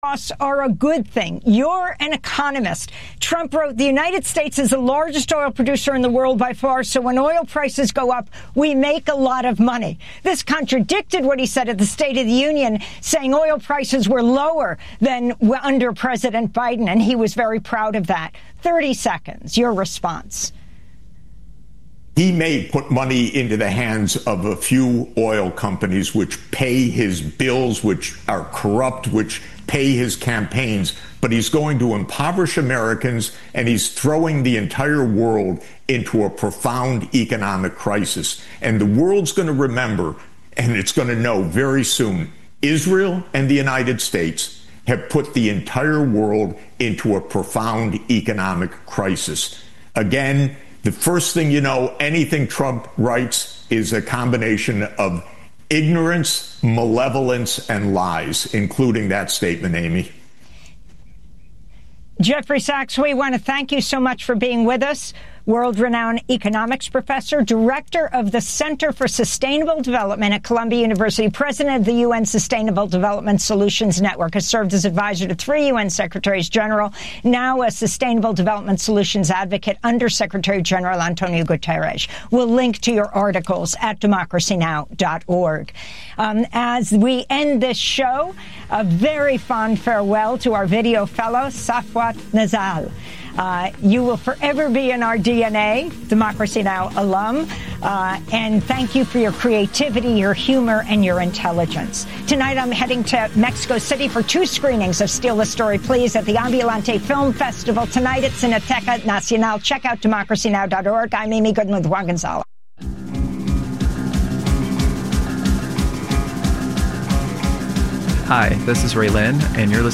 Genius Box: Interview with Cindy Cohn from EFF!